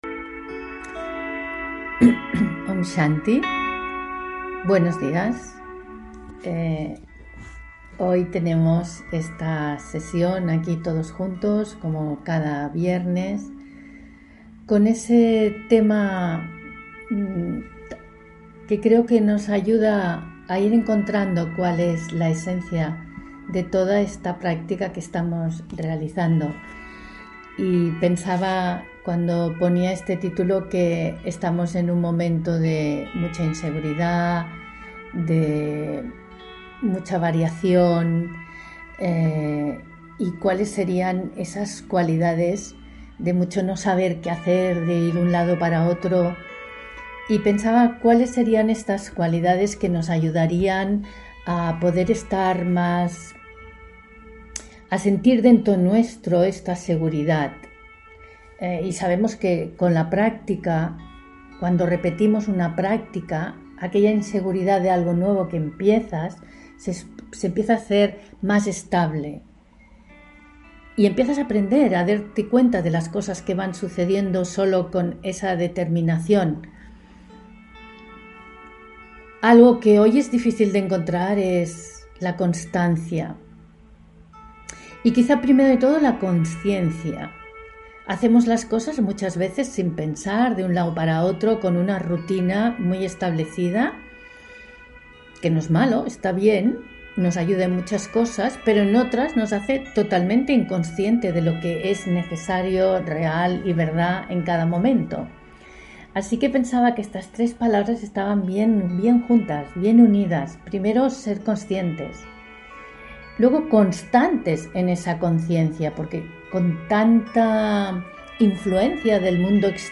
Meditación Raja Yoga y charla: Constantes, conscientes y consistentes (4 Junio 2021) On-line desde Barcelona